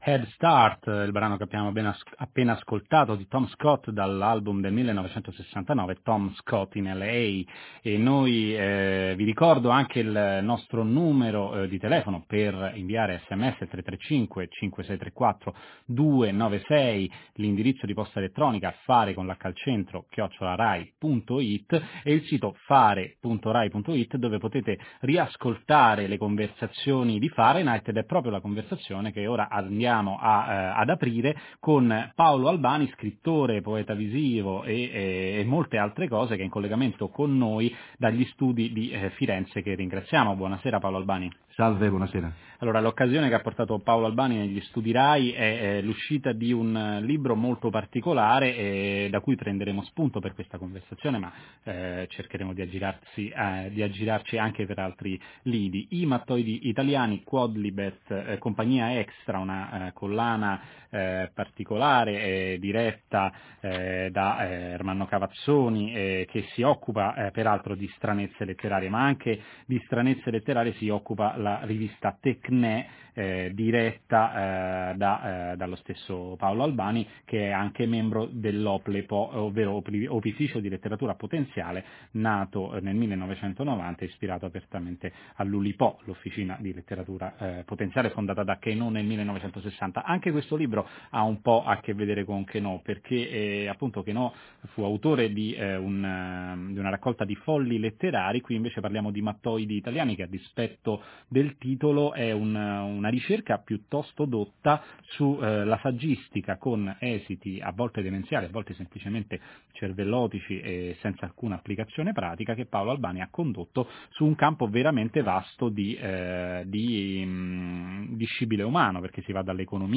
_________________________________________________________ Per ascoltare il podcast del mio intervento alla trasmissione Fahrenheit di RaiRadio3 del 31 ottobre 2012